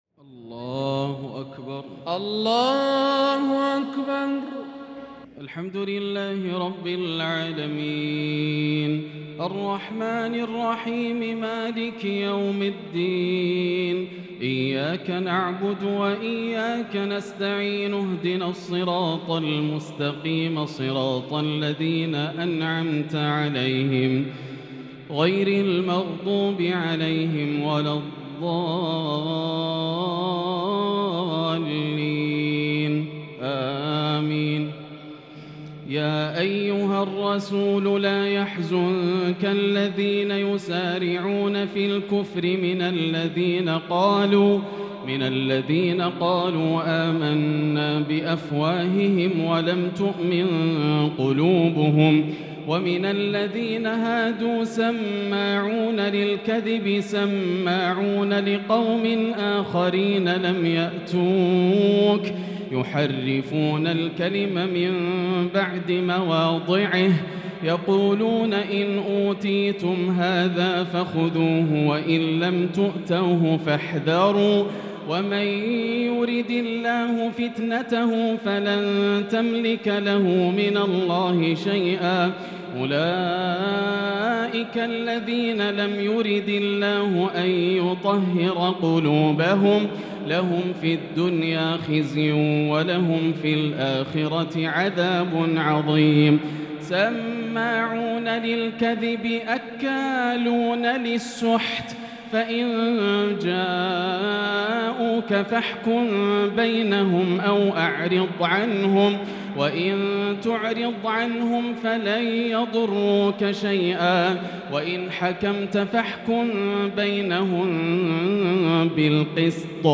تراويح ليلة 8 رمضان 1443 من سورة المائدة {41-81} | Taraweeh 8st night Ramadan 1443H Surah AlMa'idah > تراويح الحرم المكي عام 1443 🕋 > التراويح - تلاوات الحرمين